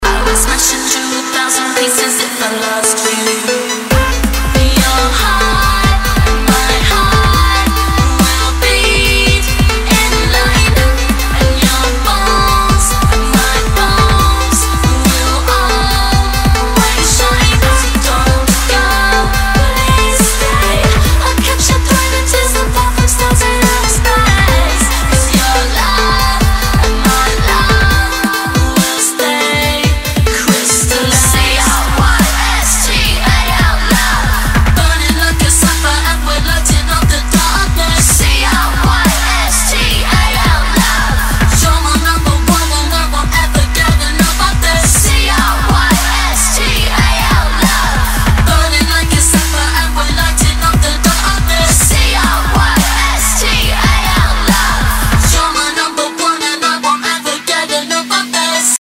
DubStep
Отличный дабстеп с красивым вокалом!